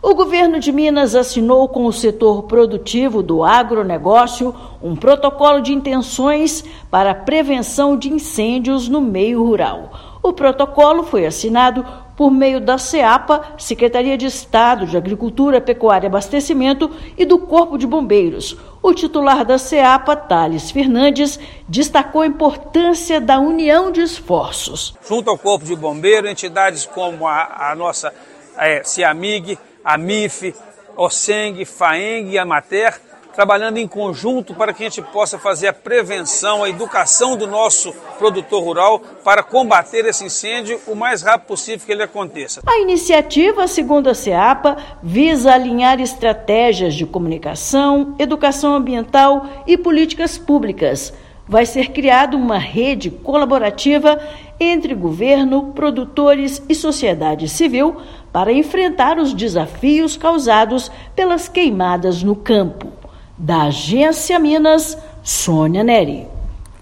Parceria foi assinada com representantes do setor produtivo do agronegócio mineiro. Ouça matéria de rádio.